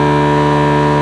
Engines
1 channel